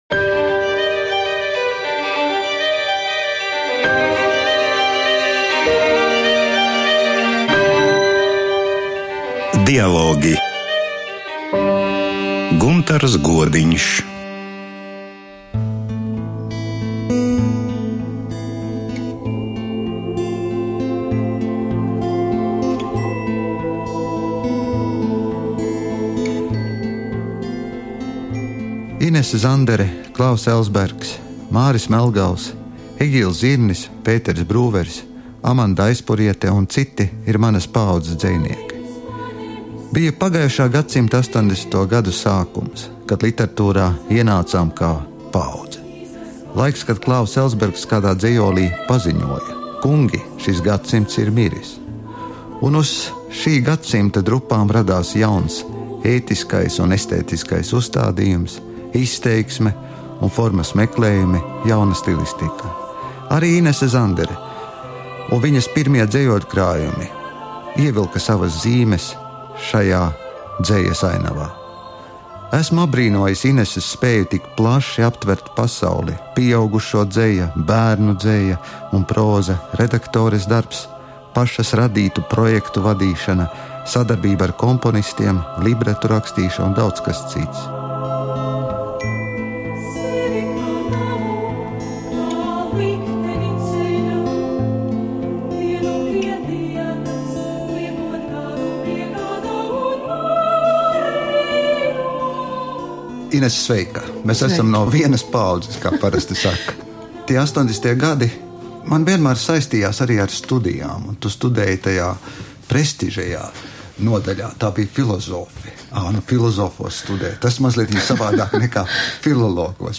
Saruna ar dzejnieci Inesi Zanderi parasti aptver plašu tēmu loku. Raidījumā viņas pārdomas par ienākšanu literatūrā, par dzeju pieaugušajiem un bērniem, par vārda un mūzikas attiecībām, par libretu rakstīšanu.